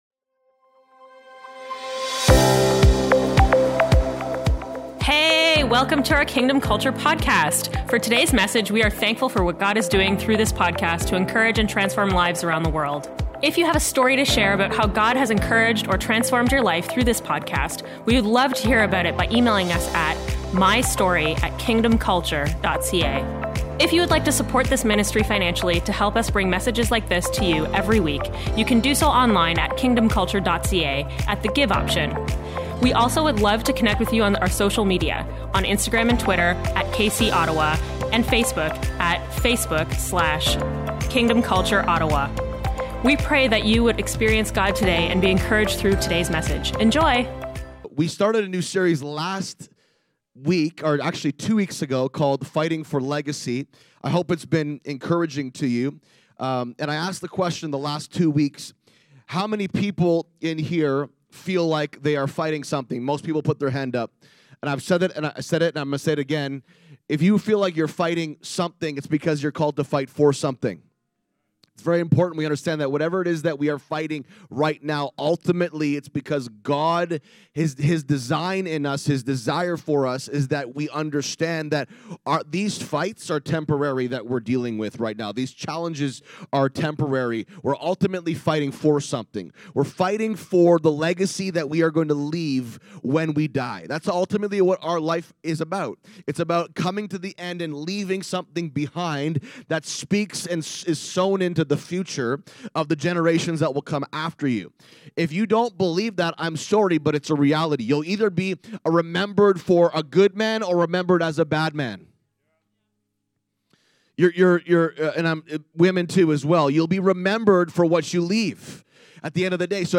Part 3 of our Fighting for Legacy Message Series.